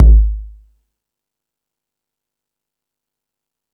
Lotsa Kicks(58).wav